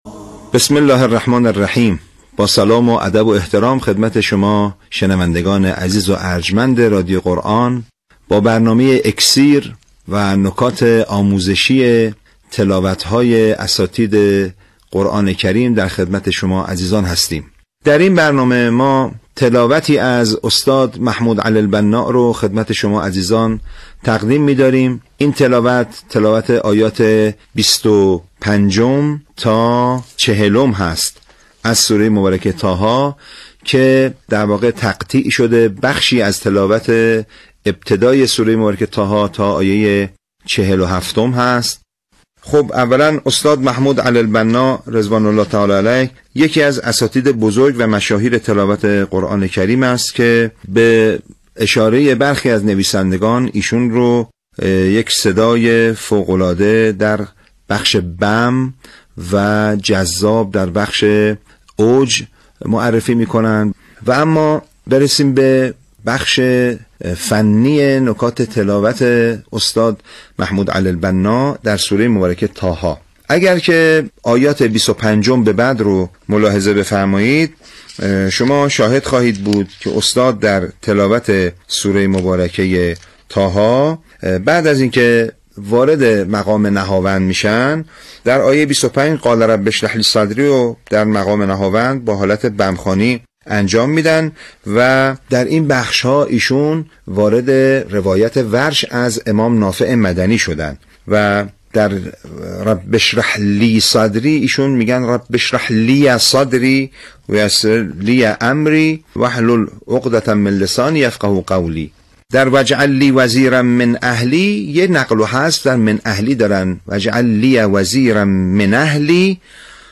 وی را صدای فوق‌العاده در بخش بم و جذاب در بخش اوج معرفی می‌کنند.
وی ادامه تلاوتش را در نغمه عراق و بعد نغمه سه‌گاه دنبال می‌کند.
صوت تحلیل تلاوت سوره طه استاد علی‌البناء